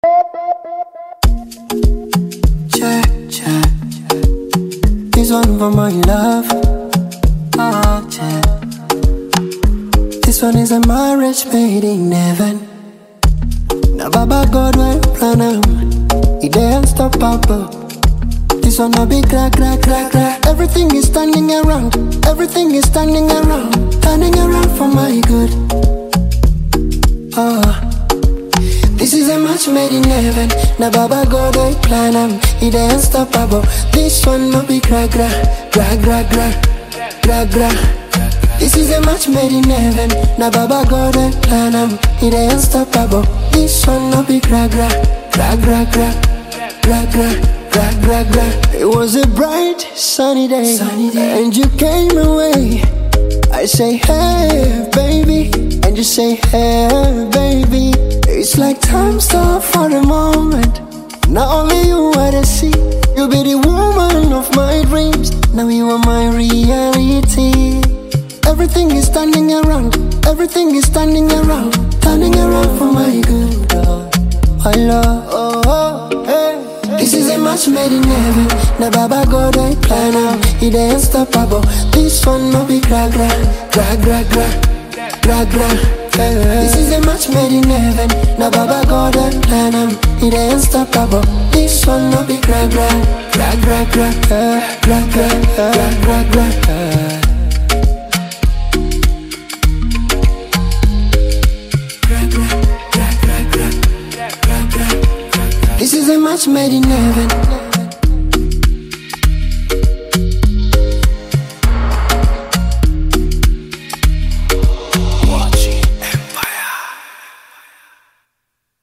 blending catchy melodies with meaningful songwriting.
Genre: Afro-Beats